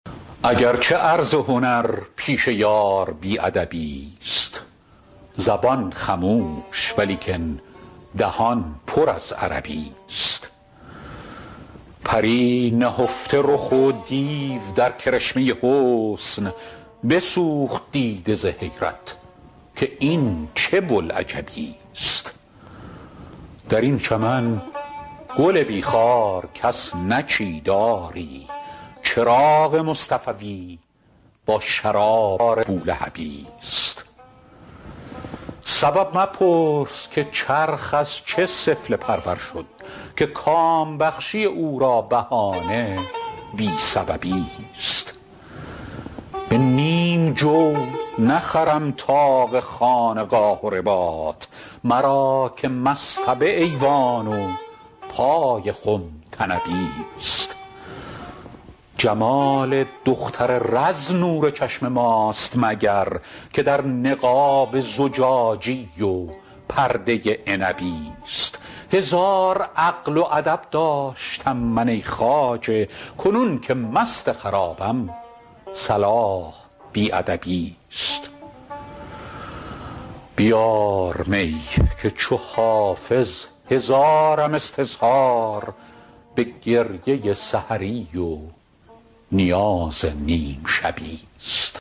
حافظ غزلیات غزل شمارهٔ ۶۴ به خوانش فریدون فرح‌اندوز